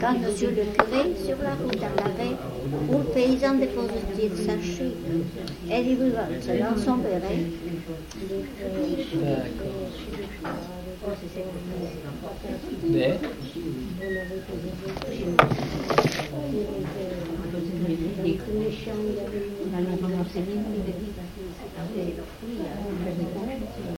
Aire culturelle : Couserans
Lieu : Castillon-en-Couserans
Genre : poésie
Type de voix : voix de femme
Production du son : récité